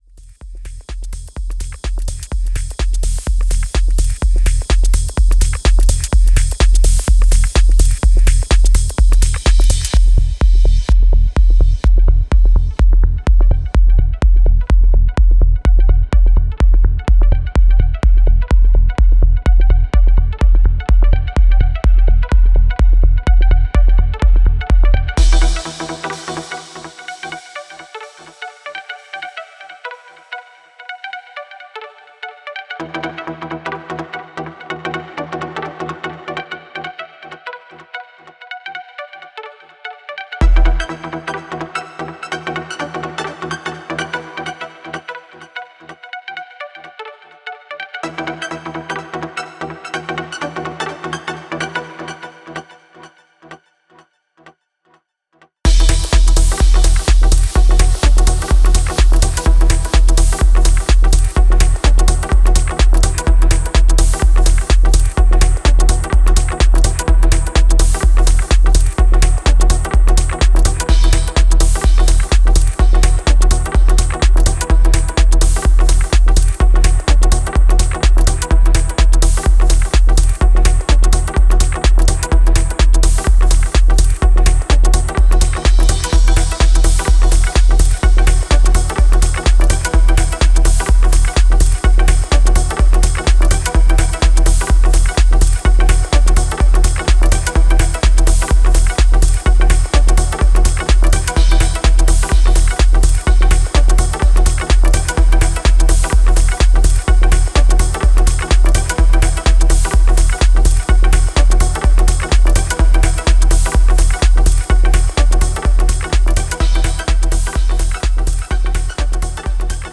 Minimal techno/Tech-house release